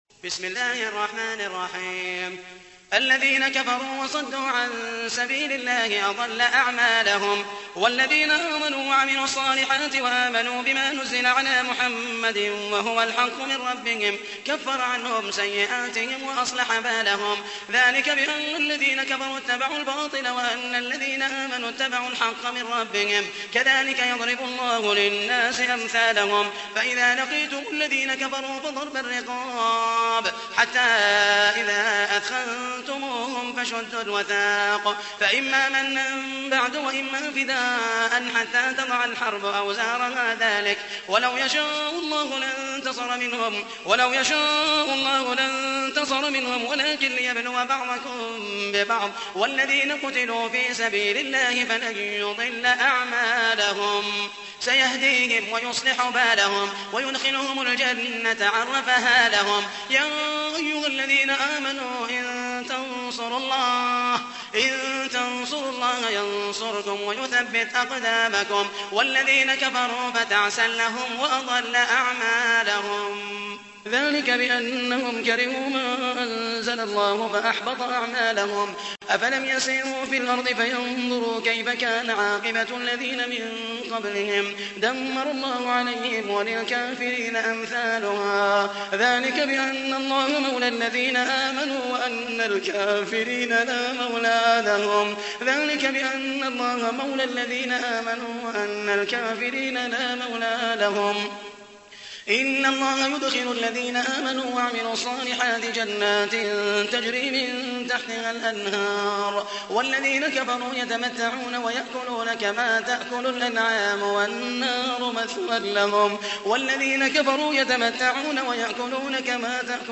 تحميل : 47. سورة محمد / القارئ محمد المحيسني / القرآن الكريم / موقع يا حسين